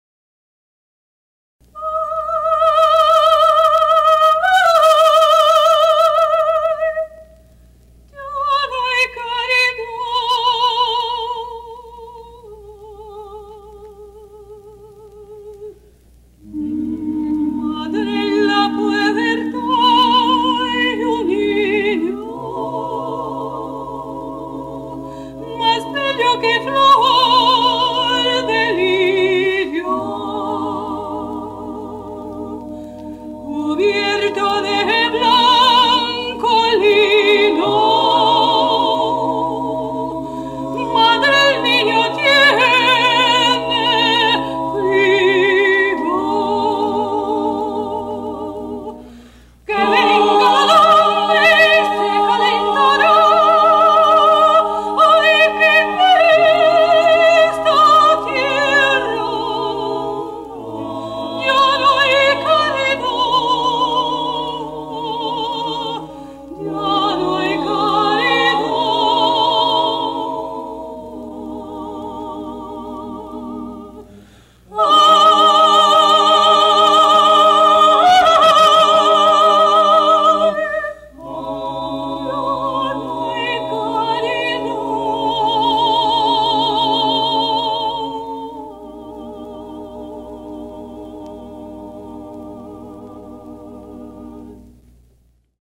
interpreta canções natalinas internacionais